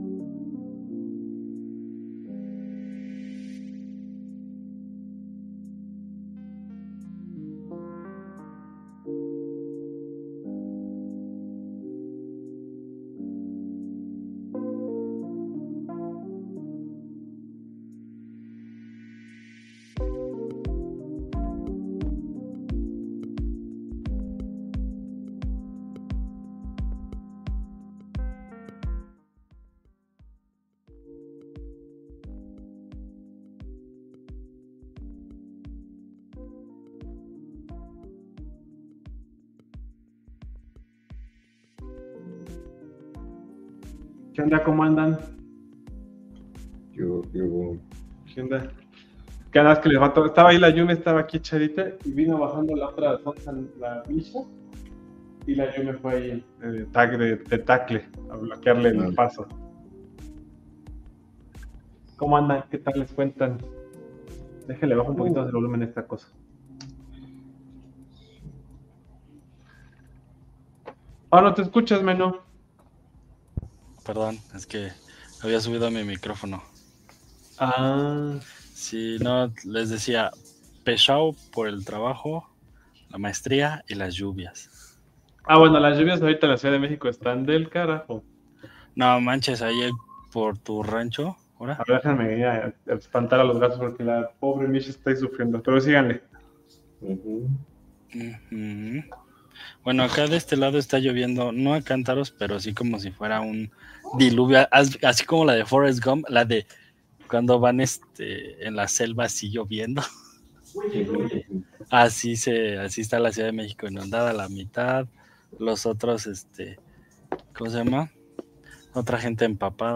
Experimentación de esta noche, acompañemos a charlar mientras jugamos Mario Kart World.